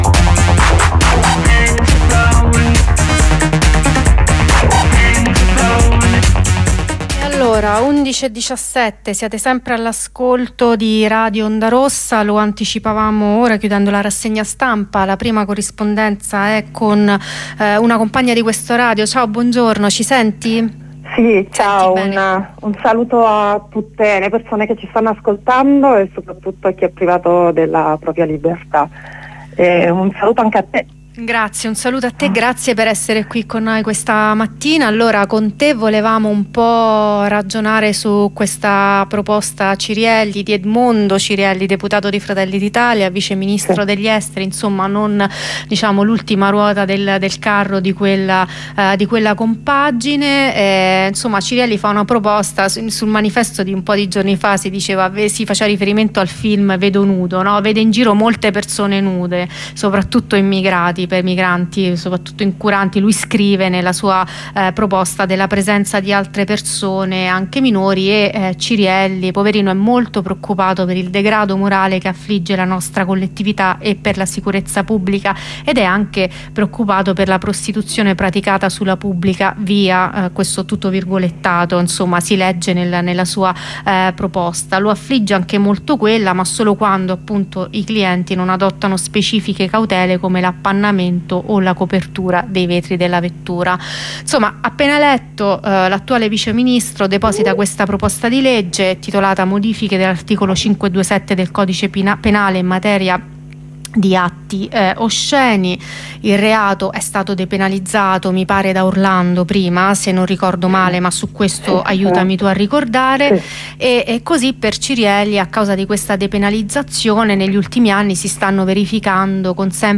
Intervento dal presidio